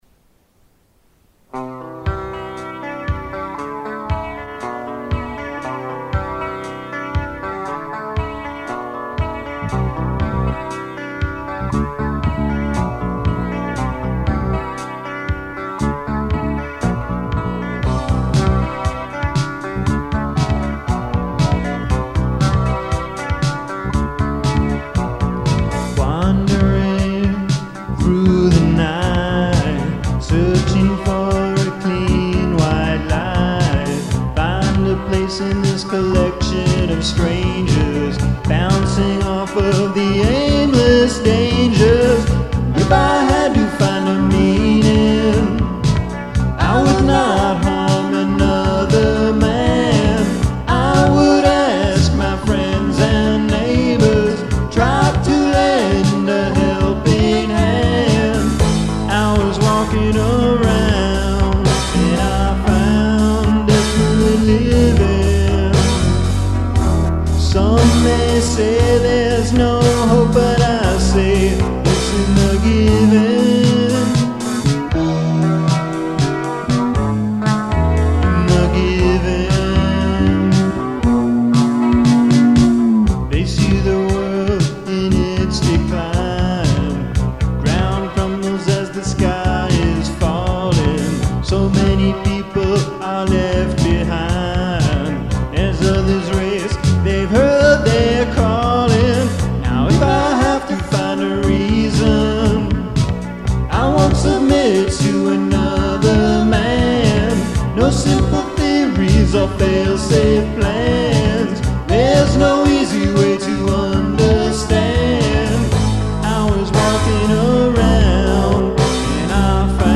keyboards
bass & vocals